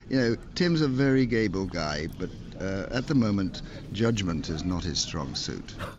Former Lib Dem leader Lord Ashdown gives his opinion on possible future Lib Dem leader Tim Farron to BBC 5Live's John Pienaar